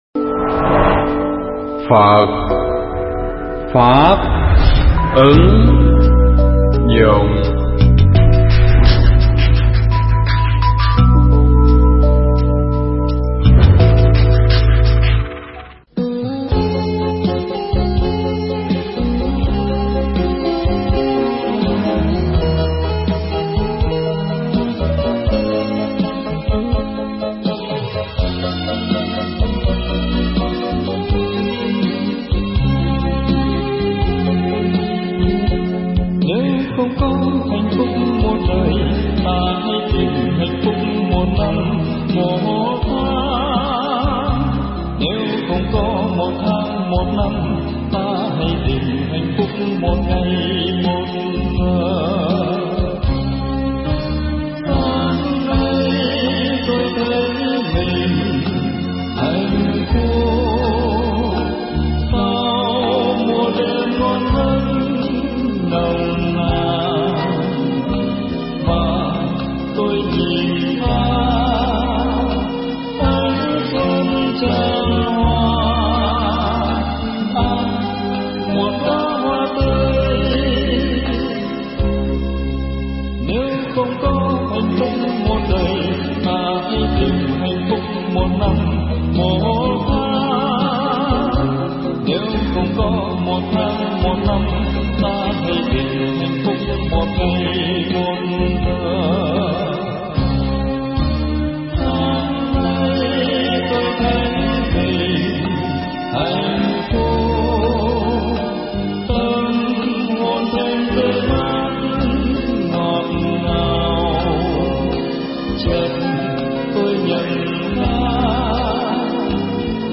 Nghe mp3 pháp thoại Vẻ Đẹp Con Người Qua Lăng Kính Đạo Phật